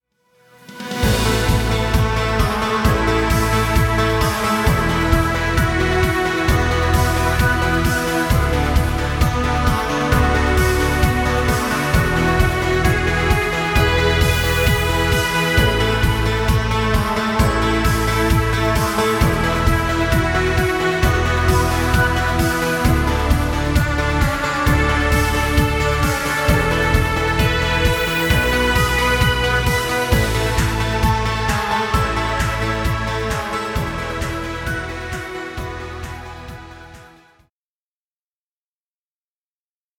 Dance music. Background music Royalty Free.